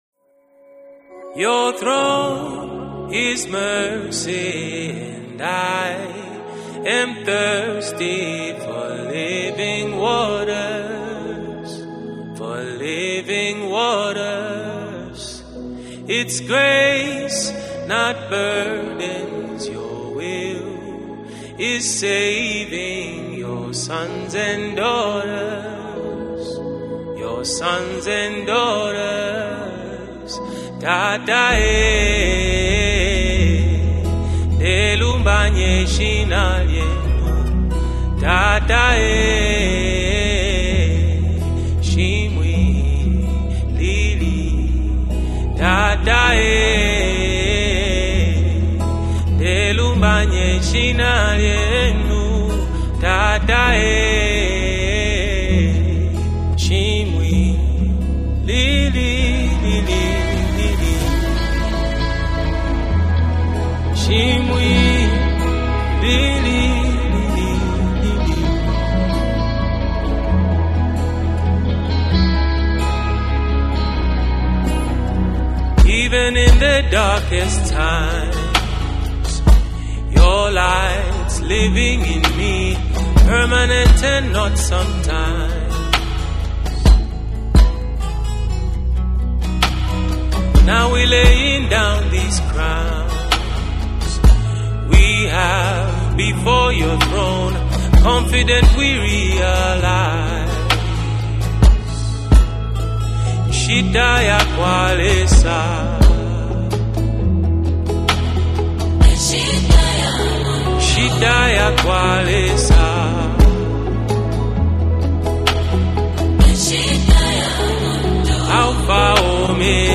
adds emotional depth through her POWERFUL VOCALS
🎼 GENRE: ZAMBIAN GOSPEL MUSIC